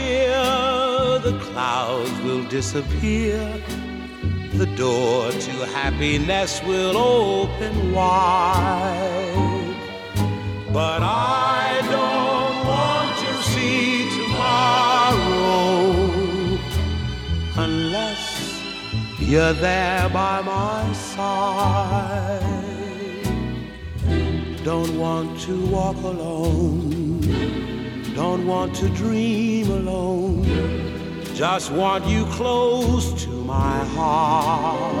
# Easy Listening